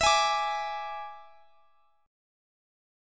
E7 Chord
Listen to E7 strummed